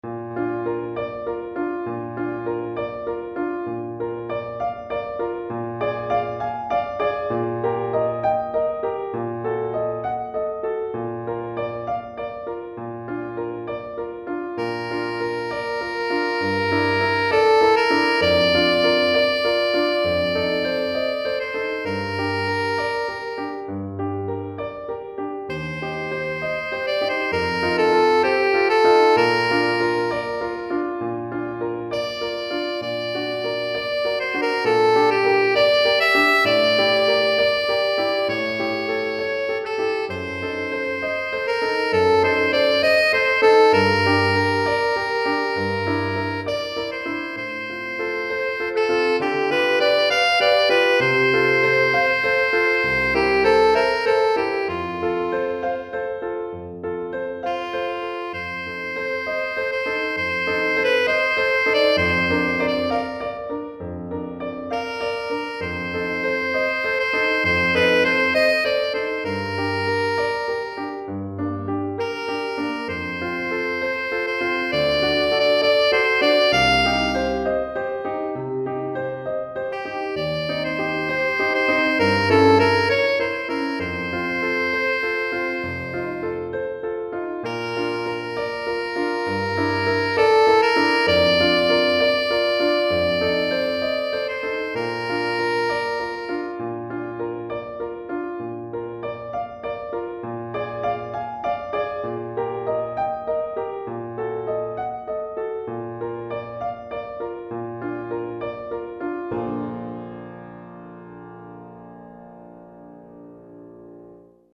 Saxophone Alto et Piano